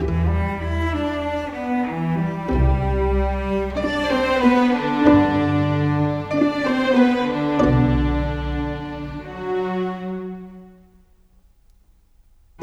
Rock-Pop 09 Strings 02.wav